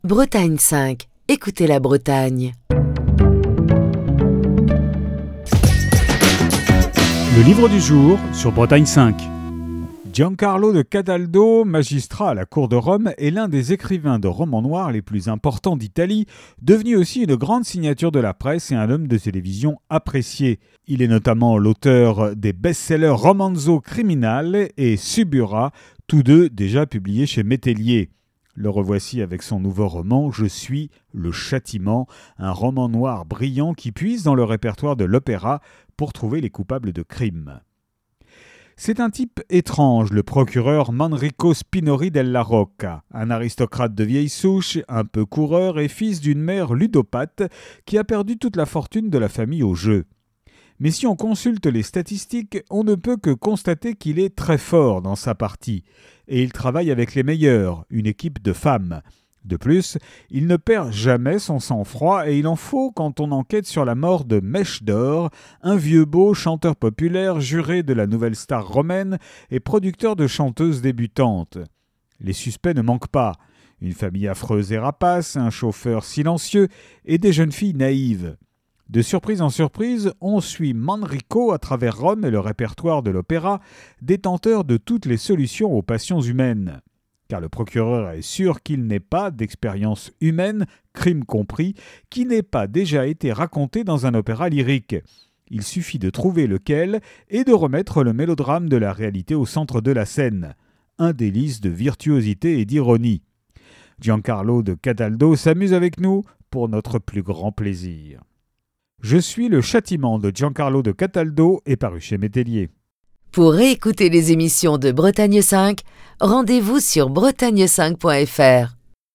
Chronique du 18 avril 2023.